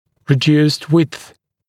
[rɪ’djuːst wɪdθ][ри’дйу:ст уидс]уменьшенная ширина